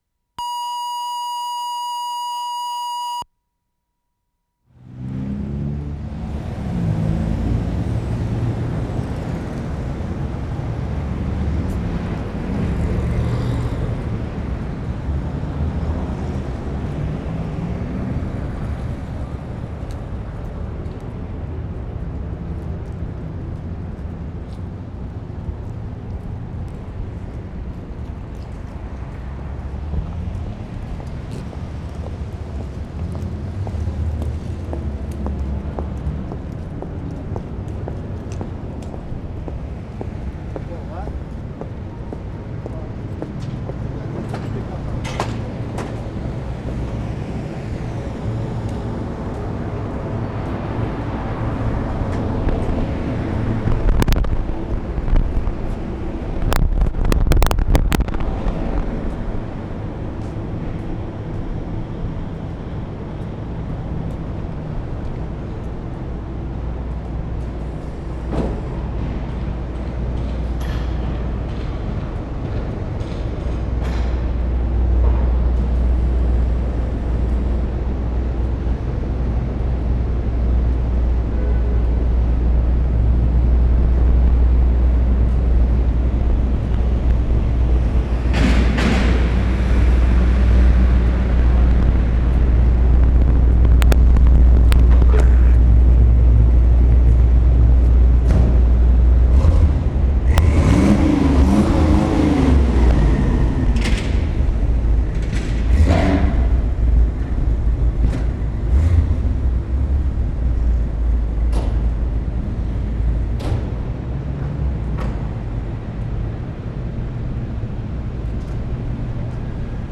DOWNTOWN SOUNDWALK Feb. 12, 1973
3. Interesting ambient textures and changes in the hotel. Good variety of acoustic spaces.
1'00" walking down alley to the Hotel Vancouver.
2'15" entering hotel, bad disturbances on microphones before and while entering.
3'45" elevator bell, enter elevator -- sudden change to quiet, absorbent ambience.
5'40" opening a window shade.
7'10" sudden ambience change, walking along corridor.
7'30" into bare hall, window open to city, recording city ambience from 14th floor.